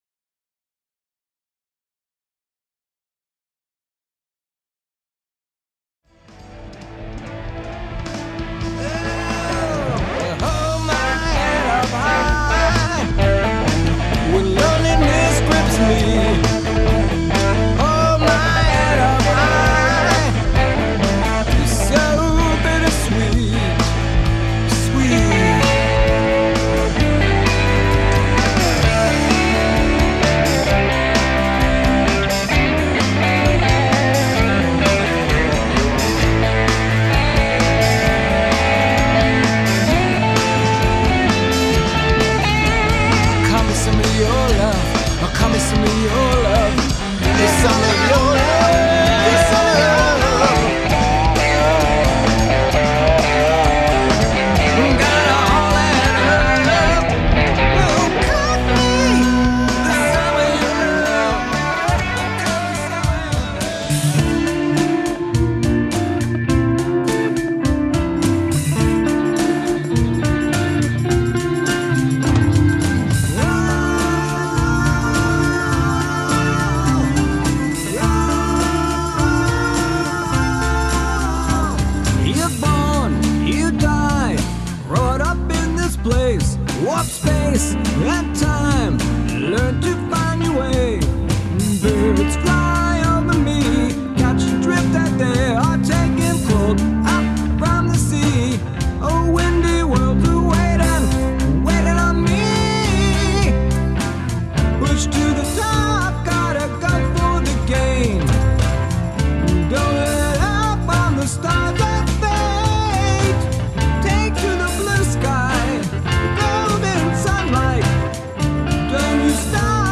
an eclectic hybrid of rock